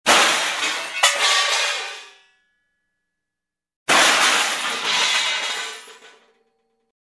Звуки столкновения
9. Вариант с разлетающимися автодеталями